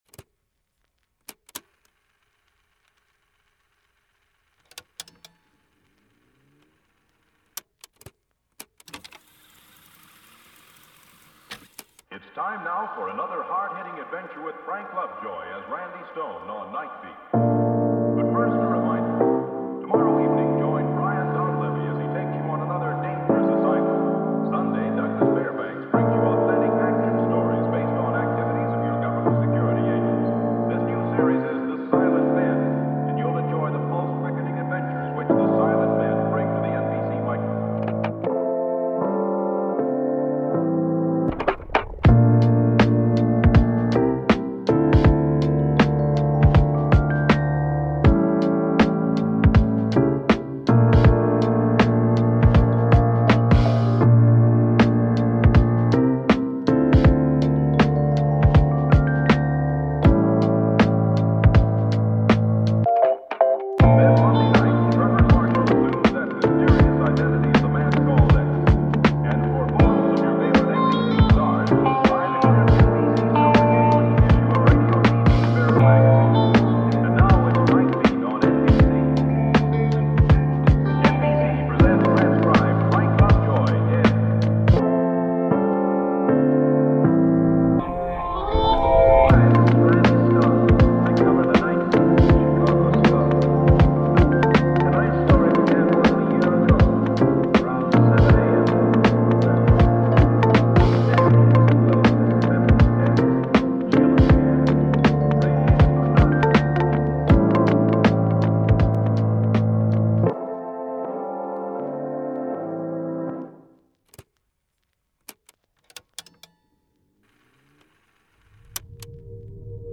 Mozart Violon : Étude Sereine